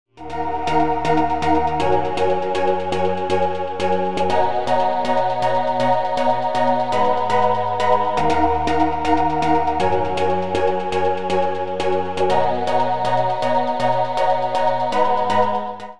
▶Clipのプリセットフレーズ